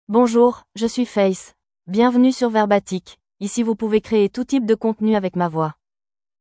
FemaleFrench (France)
FaithFemale French AI voice
Faith is a female AI voice for French (France).
Voice sample
Faith delivers clear pronunciation with authentic France French intonation, making your content sound professionally produced.